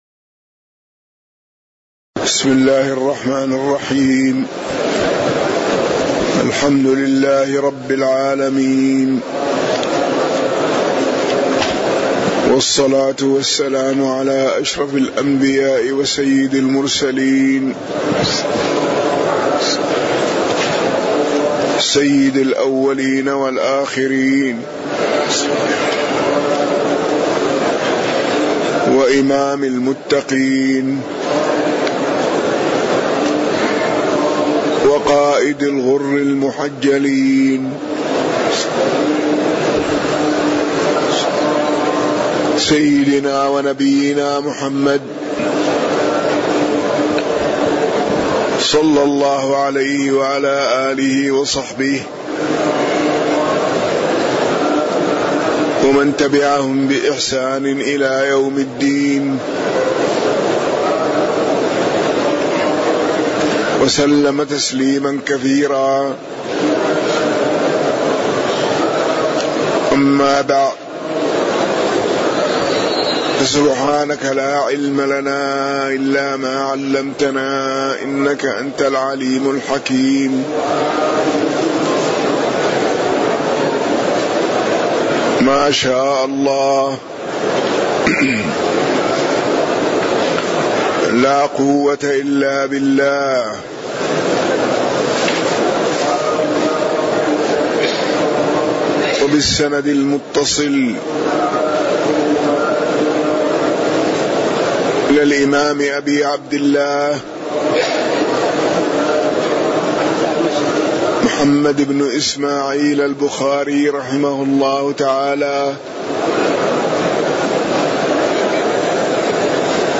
تاريخ النشر ١٠ رجب ١٤٣٩ هـ المكان: المسجد النبوي الشيخ